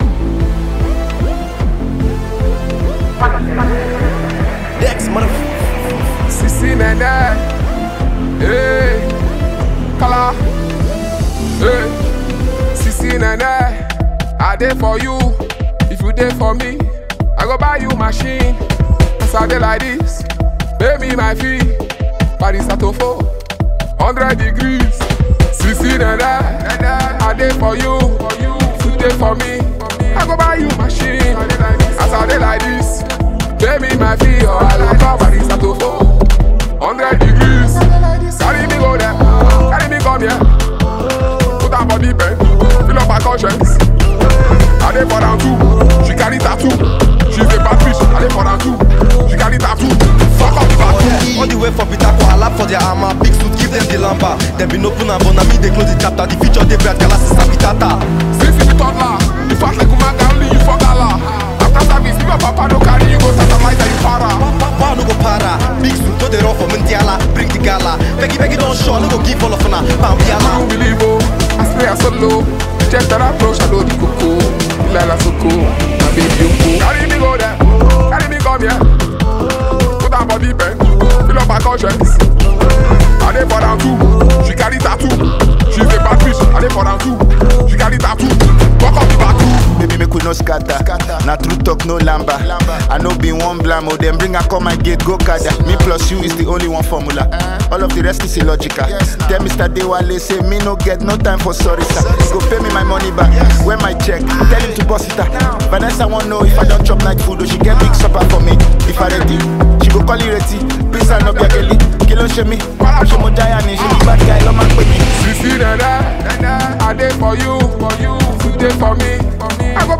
Nigerian rapper